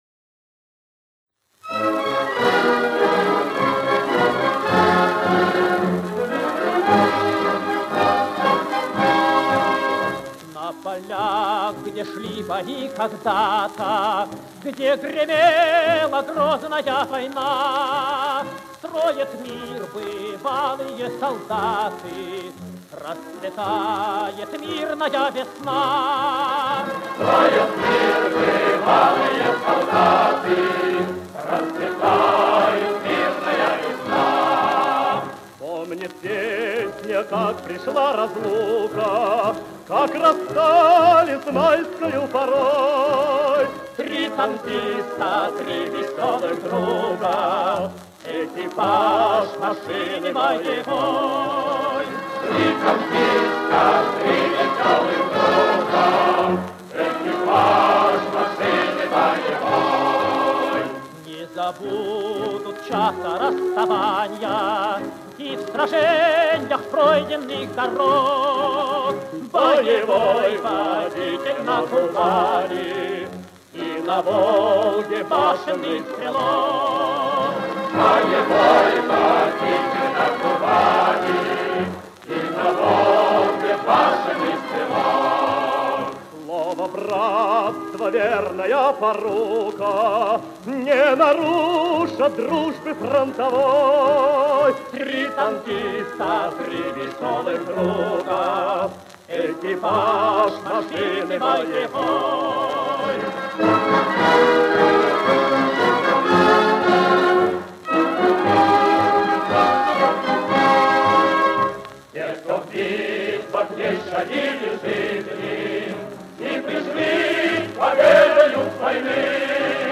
Хор и оркестр